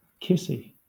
Ääntäminen
Ääntäminen Southern England Haettu sana löytyi näillä lähdekielillä: englanti Käännöksiä ei löytynyt valitulle kohdekielelle. Kissy on sanan kiss hellittelymuoto.